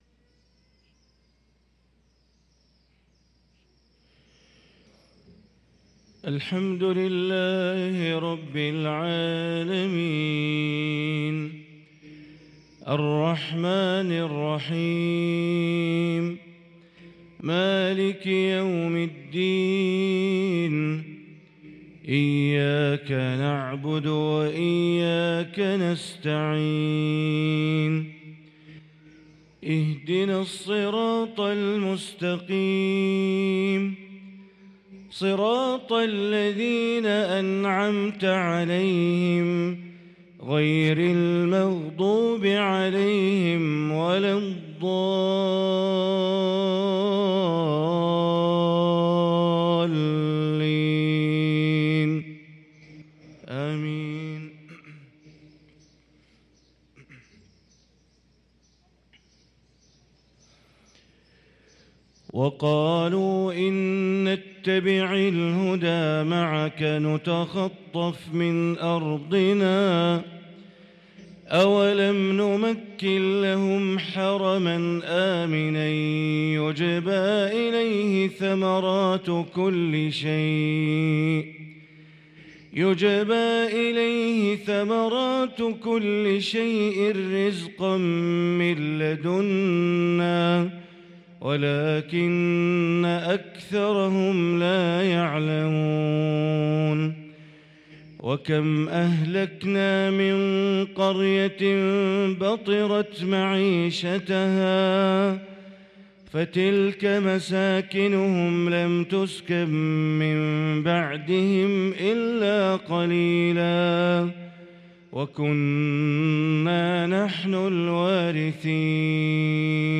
صلاة الفجر للقارئ بندر بليلة 28 جمادي الآخر 1445 هـ